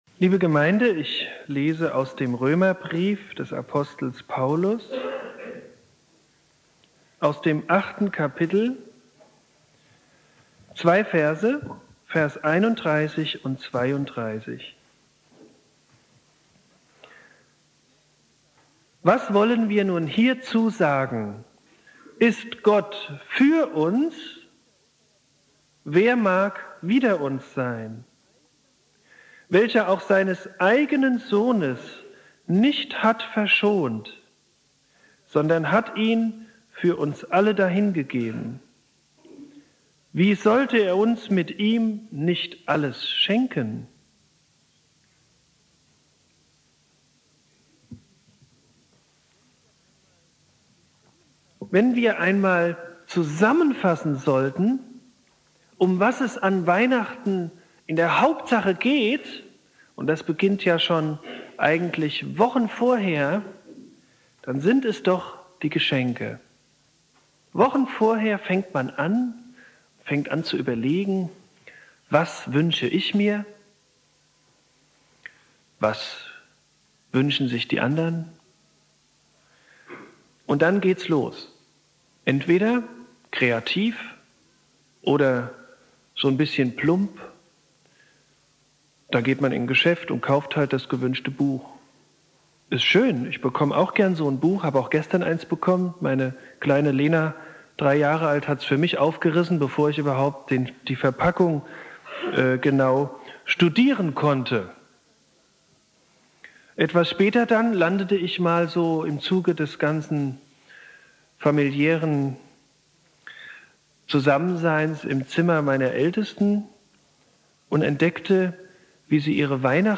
Predigt
1.Weihnachtstag Prediger